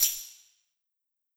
Cardi Tam Hit 2.wav